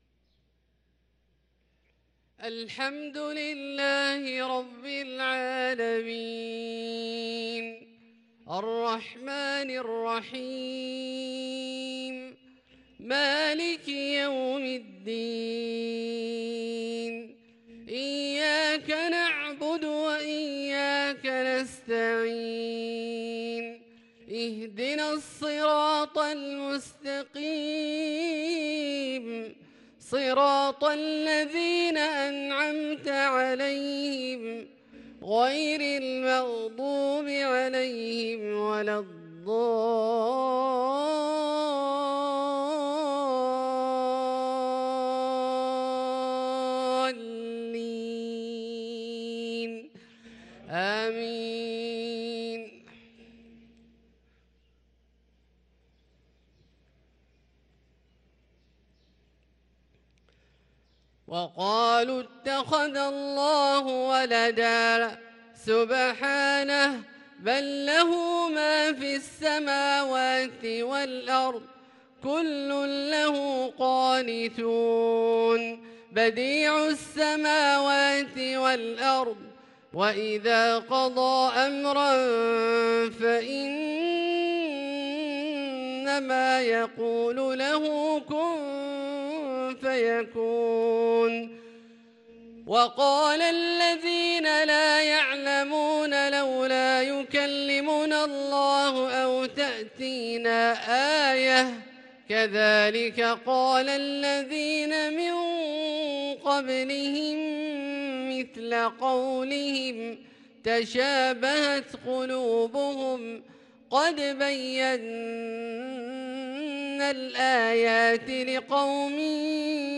صلاة العشاء للقارئ عبدالله الجهني 8 جمادي الآخر 1444 هـ